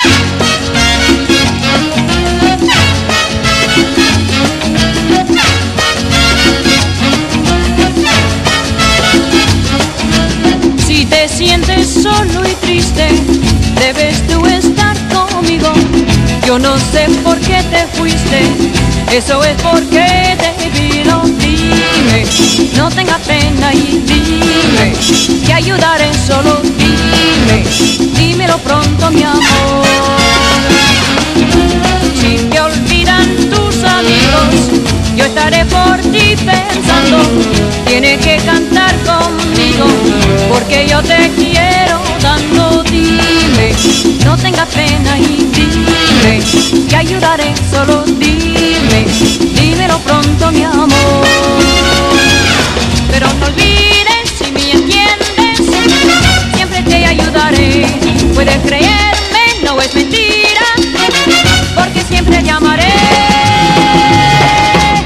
ROCK / PUNK / 80'S～ / GARAGE PUNK